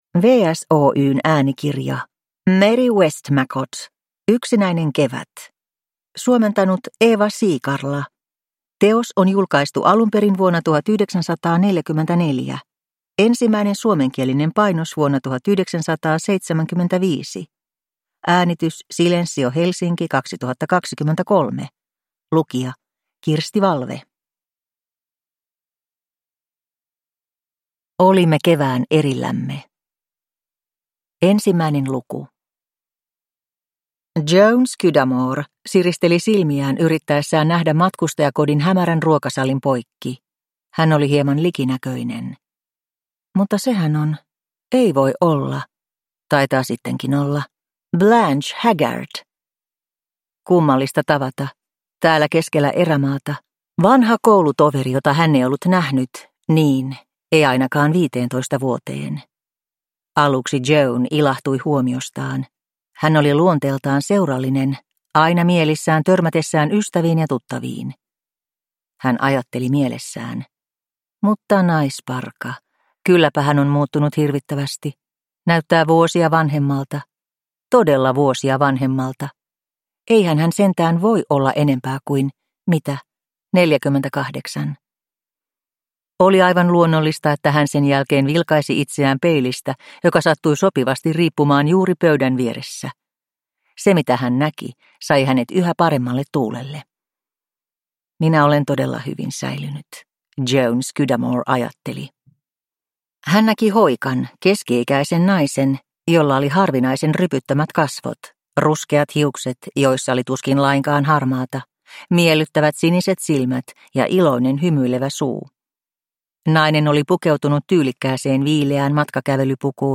Yksinäinen kevät – Ljudbok